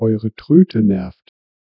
sample02-griffin-lim.wav